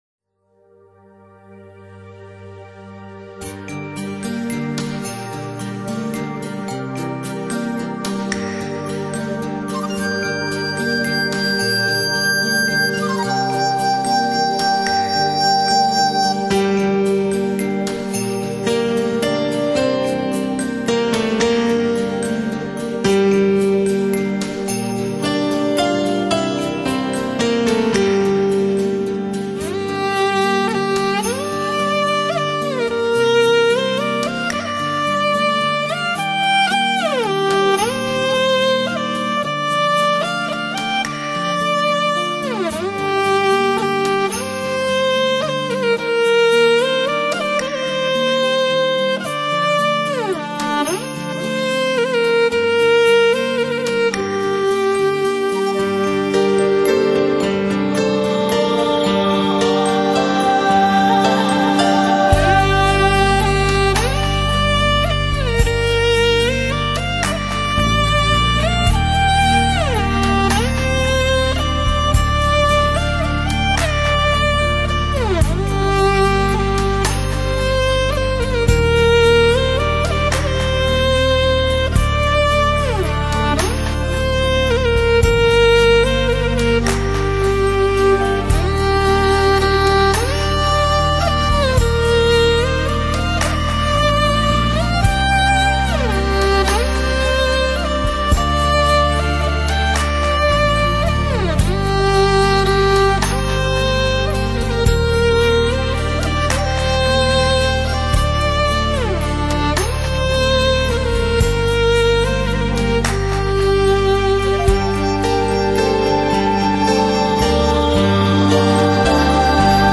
那暗含感伤又心潮跌宕的旋律，即使在一些欢快的节奏下，也仍能让我们听出草原民族的内心情愁。